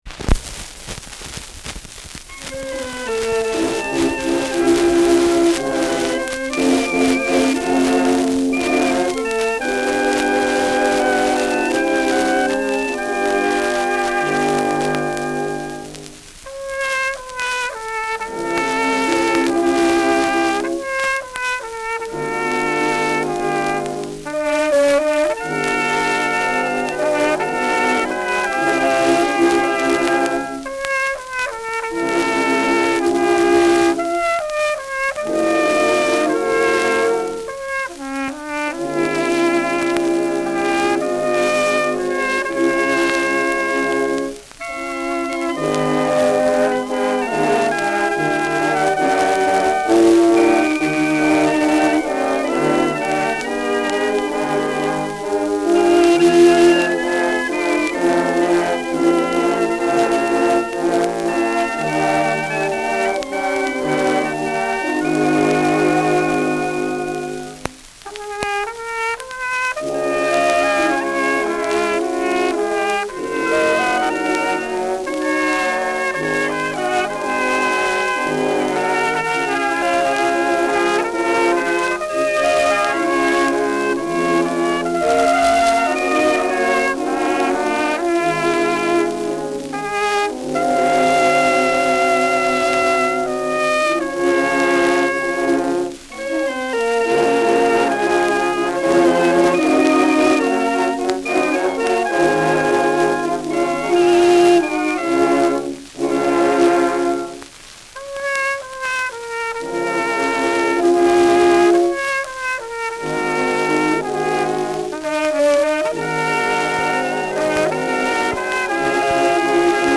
Lied für Trompete
Schellackplatte